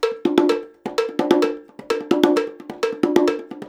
130BONGO 02.wav